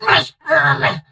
cordelius_hurt_vo_04.ogg